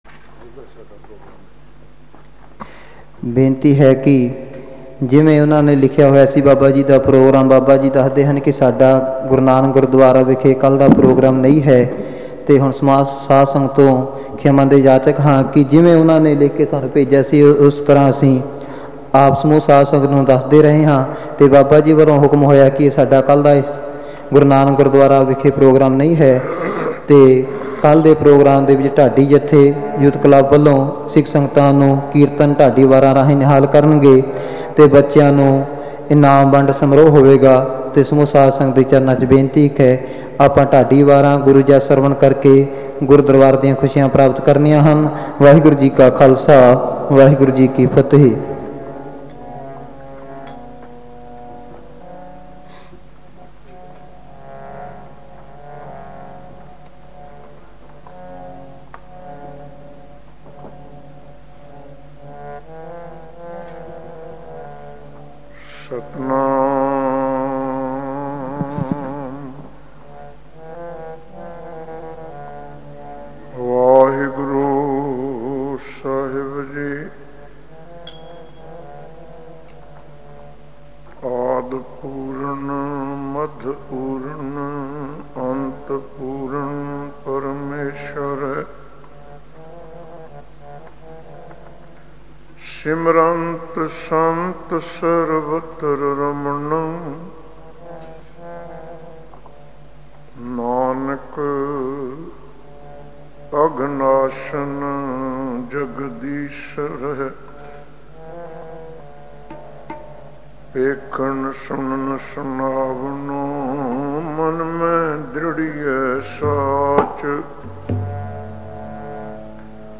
Kirtan with katha
Genre: Gurmat Vichar